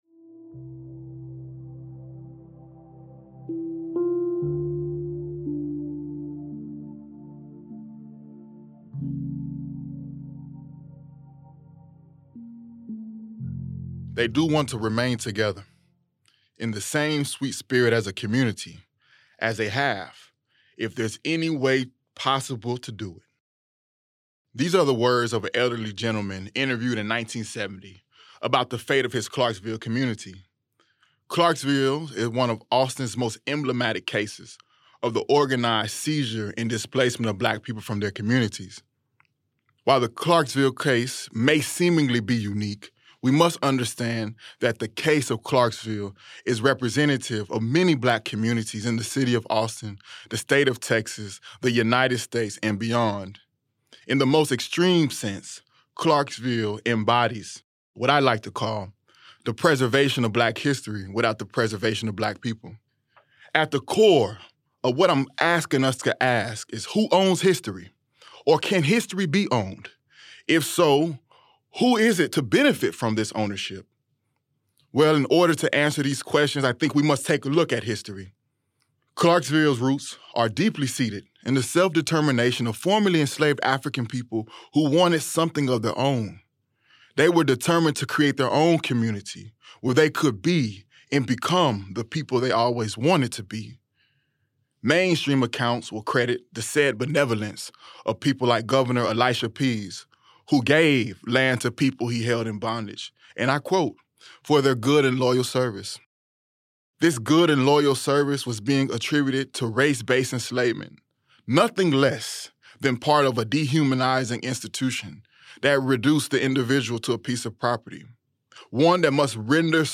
Download - Joy (Advent Spoken Word) | Podbean